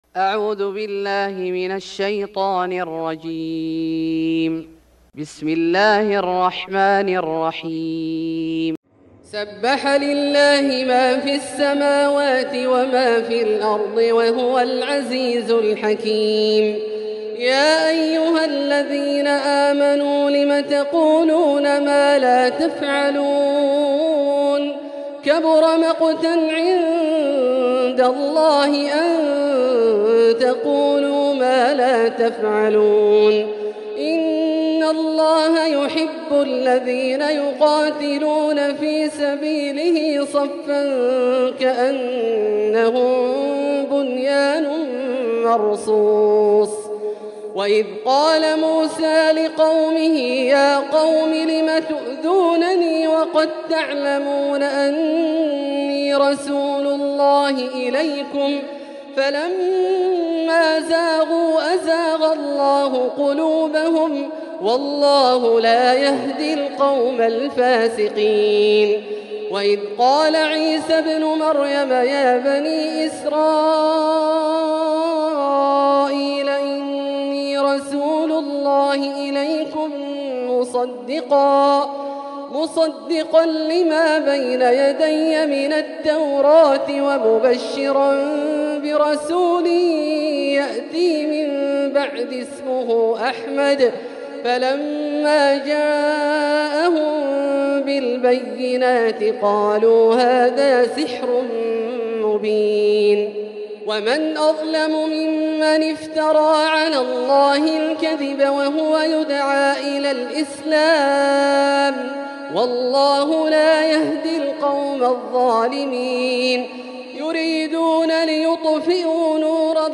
سورة الصف Surat As-Saff > مصحف الشيخ عبدالله الجهني من الحرم المكي > المصحف - تلاوات الحرمين